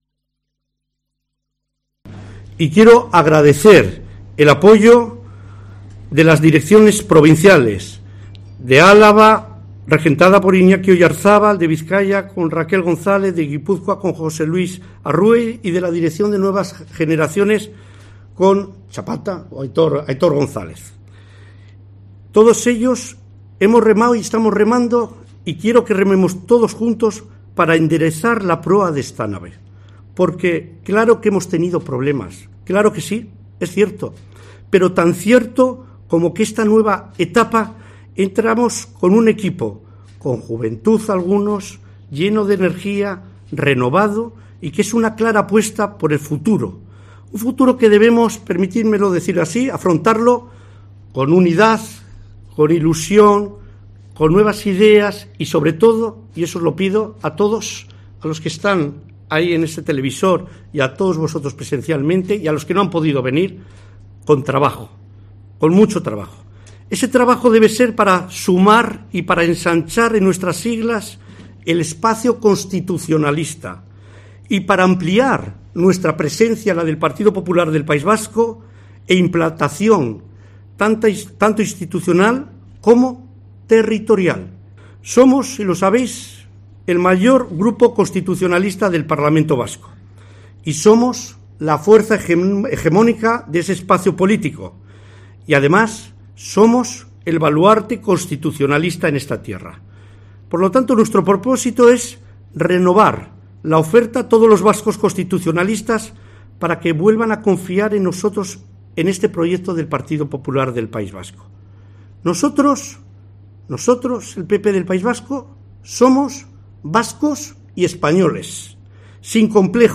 Intervención de Carlos Iturgaiz en la Junta Directiva dl PP vasco